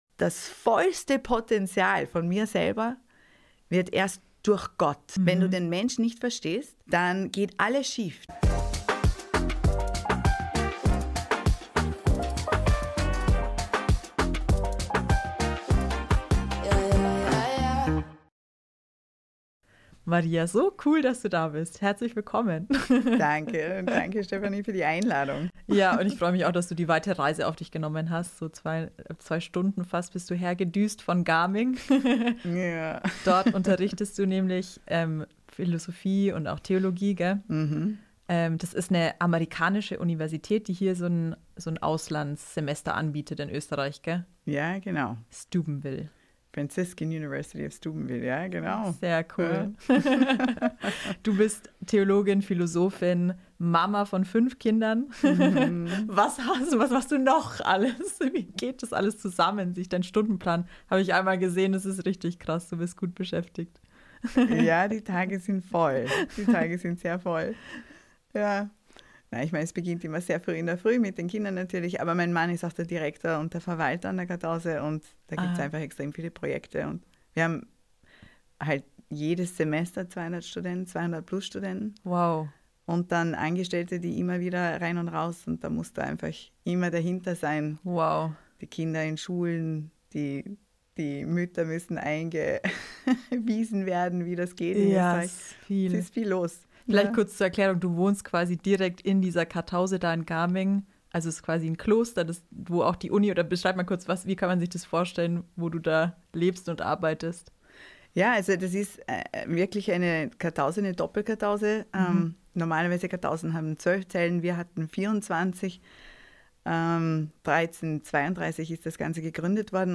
Also: Was ist der Mensch eigentlich? In diesem Gespräch geht es um: